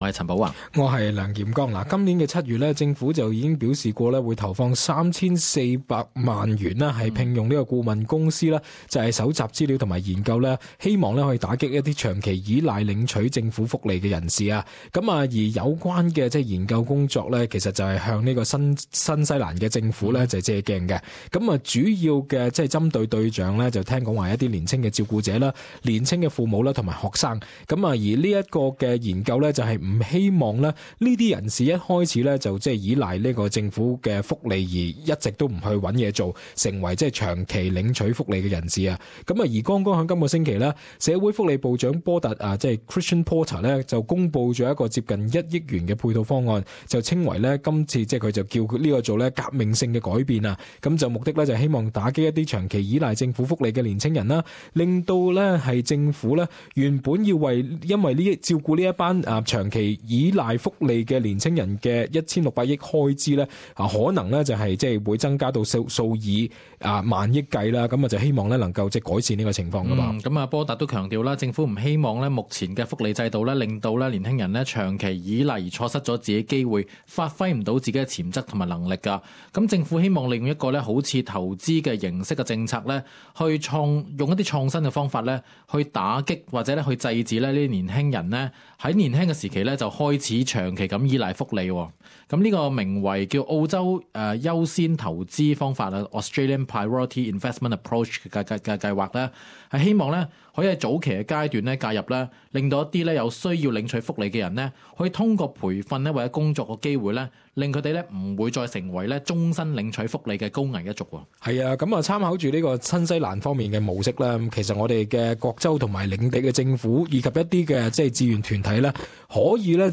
与听众朋友讨论政府的方案是否可取？